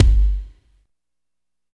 74 KICK 2.wav